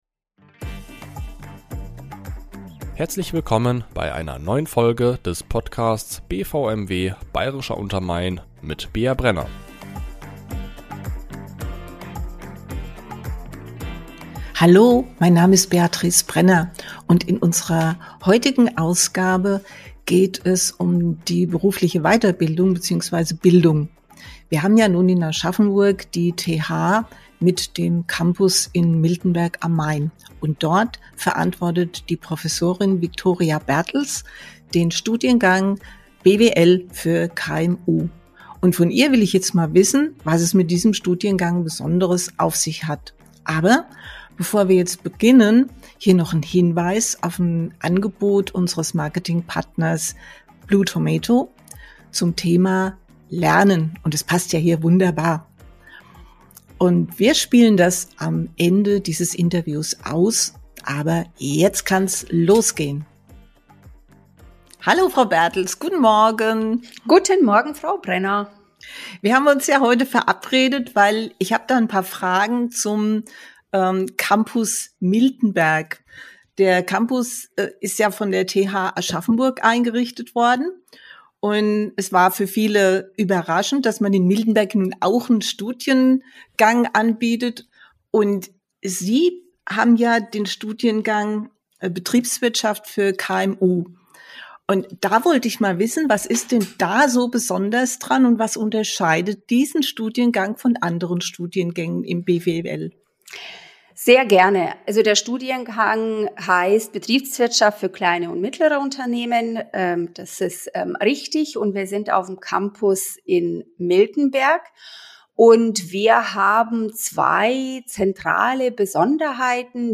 direkt vom Campus. Diese Folge unterstützt unser Marketing-Partner Blue Tomato und es gibt am Ende des Interviews ein limitiertes Angebot für euch zu einem kostenlosen Live Online Workshop zum Thema "New Work".